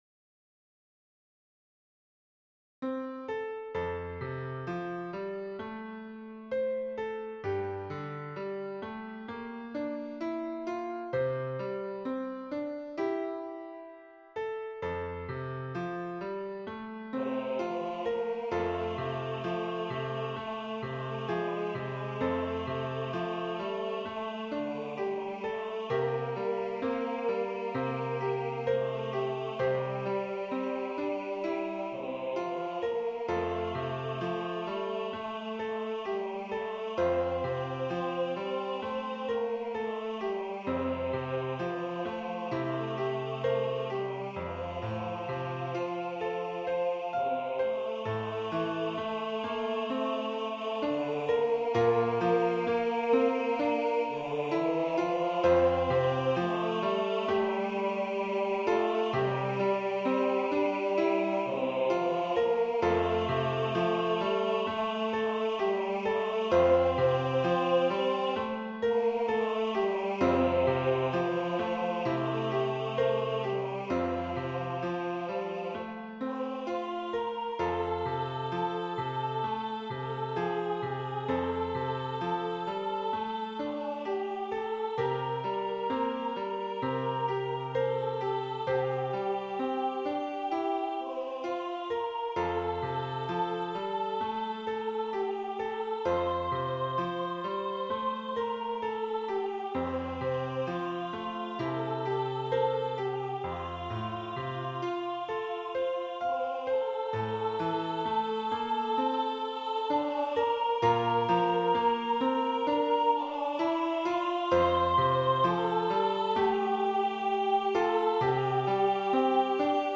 SAB
This is a public domain hymn, originally written in a four part hymn style. As with my other arrangments, I wanted to arrange it in SAB in such a way as smaller choirs (without enough men's voices) could sing it.
Voicing/Instrumentation: SAB